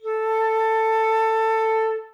plt.title('A4 Played on Flute')
flute-A4.wav